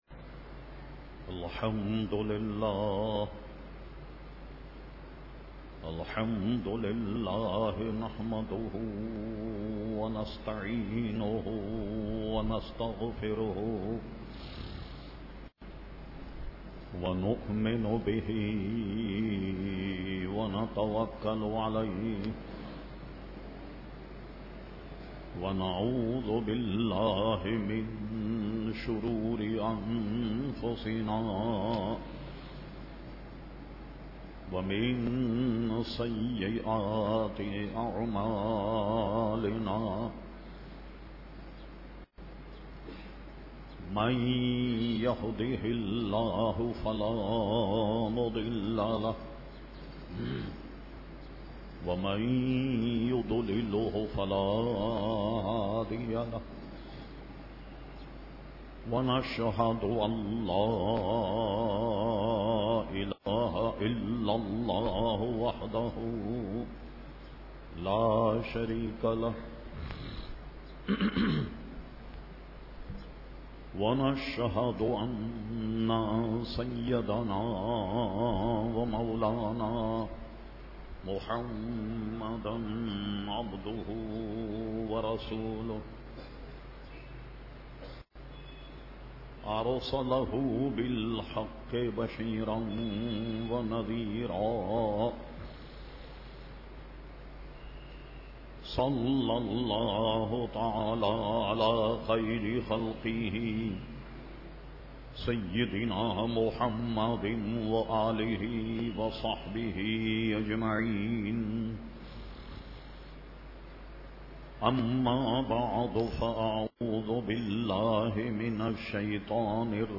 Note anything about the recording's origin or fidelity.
At Memon Masjid Muslehuddin Garden Karachi on 3 July 2009